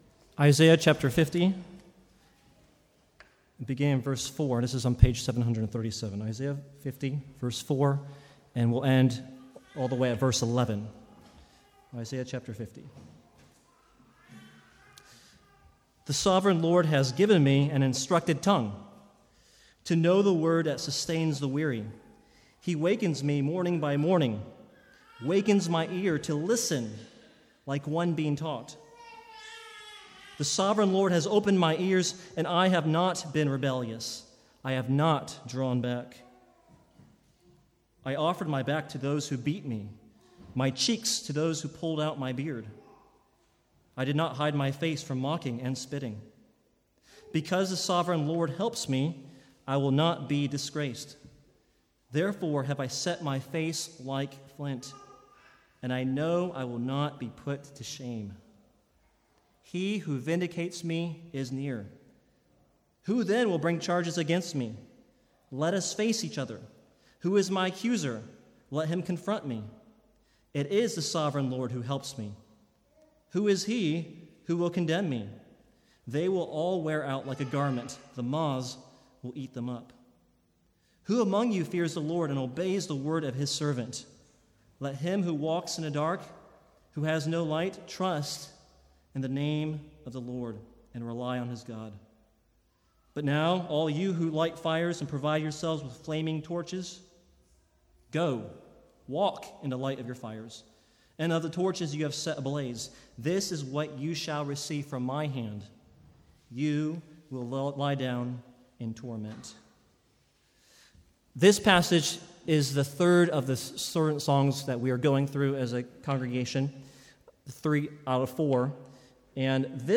Sermons | St Andrews Free Church
From the Sunday morning series in the Servant Songs of Isaiah.